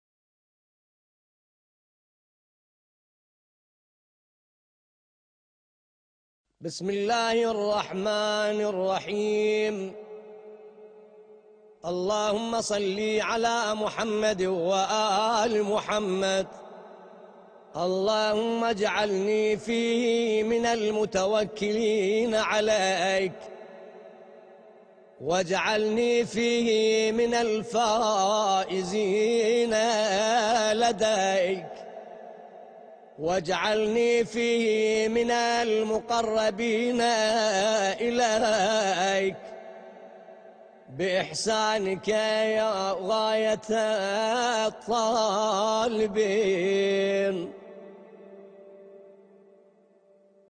دعای روز دهم ماه مبارک رمضان